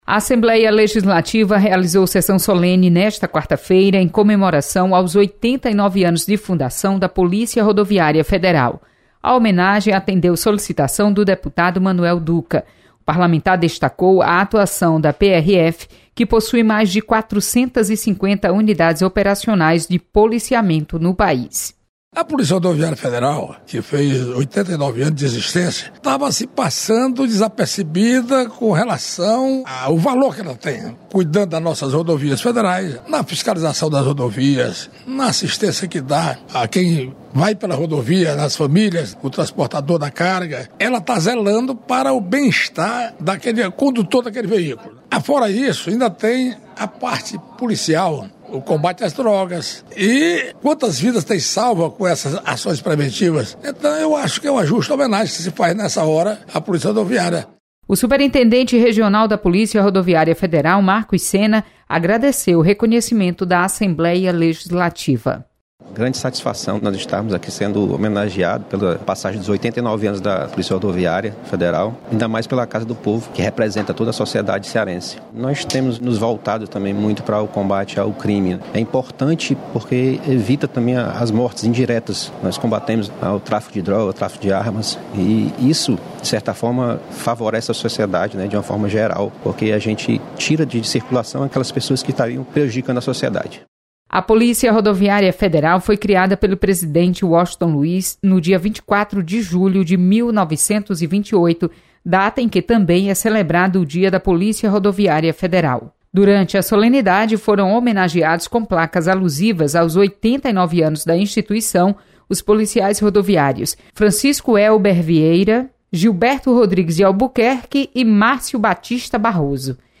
Comunicação